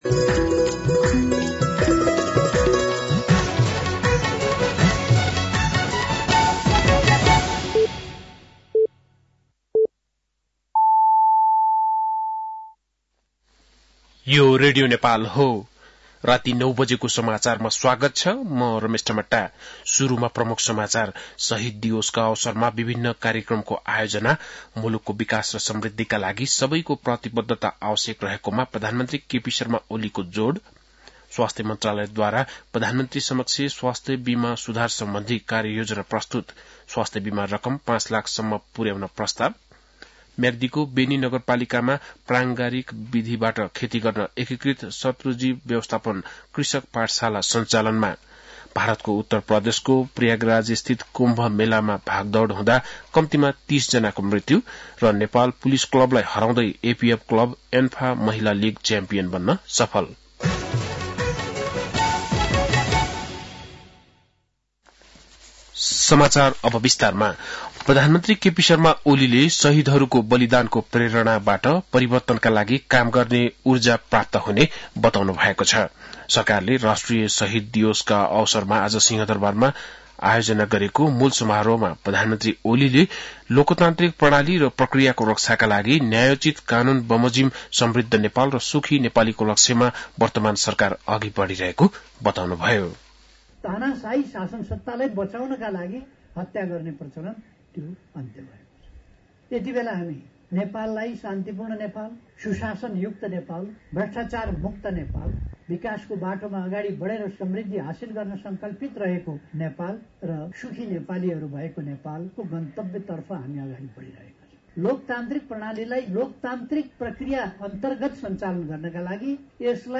बेलुकी ९ बजेको नेपाली समाचार : १७ माघ , २०८१
9-PM-Nepali-NEWS-10-16.mp3